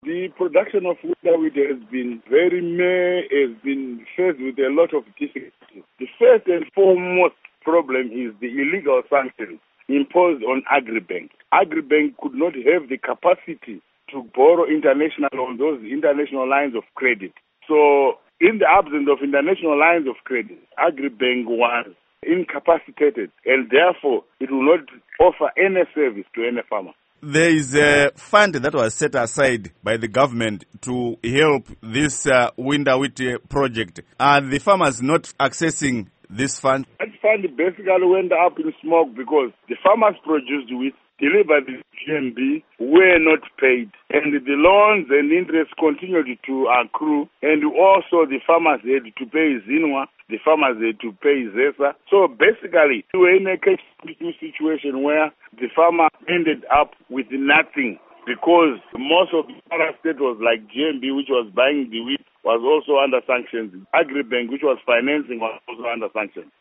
Embed share Interview With Simbaneuta Mudarikwa by VOA Embed share The code has been copied to your clipboard.